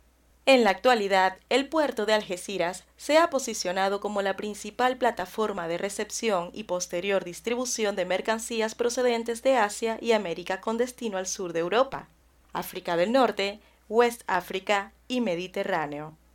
Sprechprobe: eLearning (Muttersprache):
I really like the imitations of characters, I have good diction, pronunciation, voice projection and work with a neutral accent in Spanish and English.